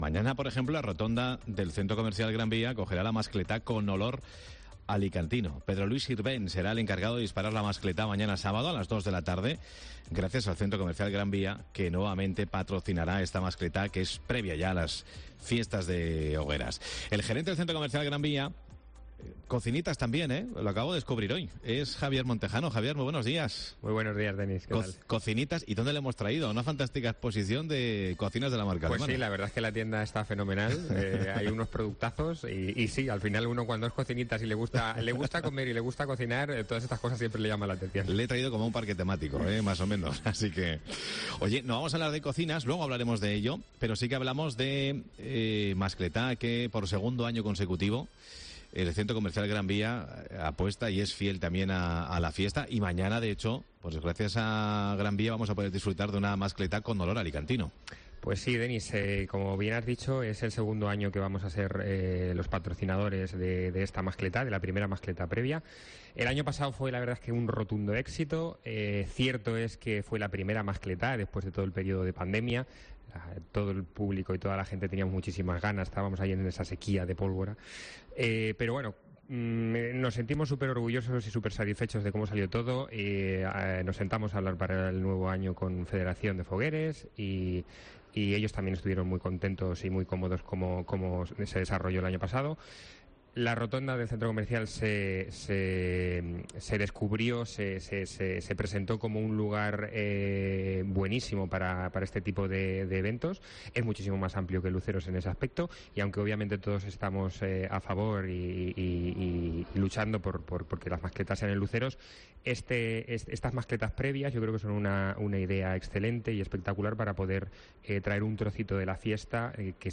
Por segundo año consecutivo el C.C. Gran Vía patrocina la gran mascletá previa a las Hogueras 2023. Escucha la entrevista